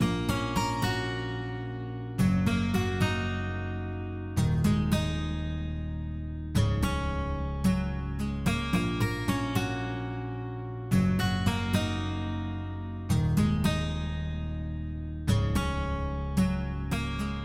简单的吉他循环
描述：Am Fm Dm Cm
标签： 110 bpm Trap Loops Guitar Acoustic Loops 2.94 MB wav Key : A